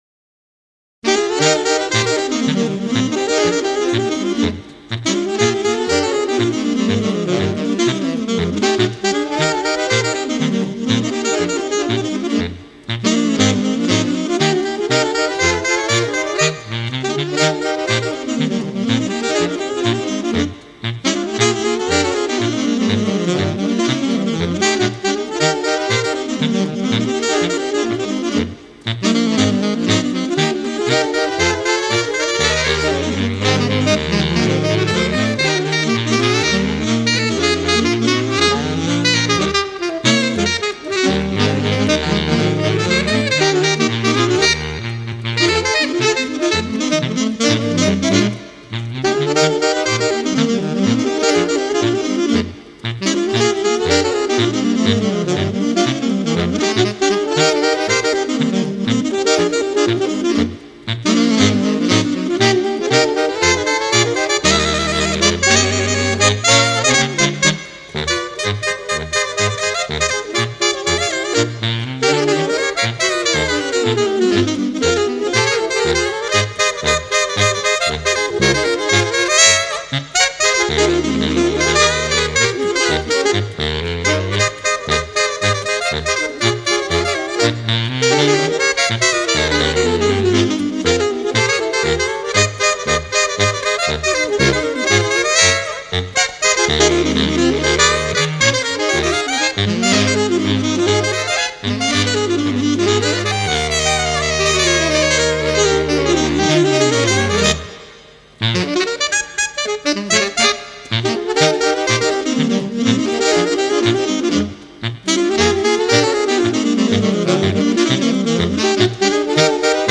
Other Saxophone Quartet Music
(Wiki: Ragtime)